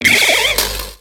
Cri de Mygavolt dans Pokémon X et Y.